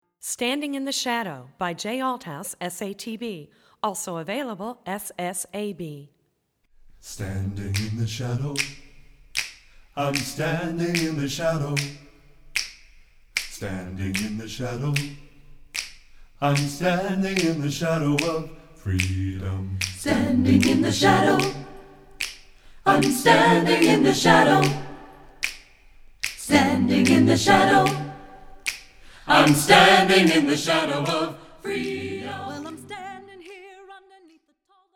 Voicing: SSAB a cappella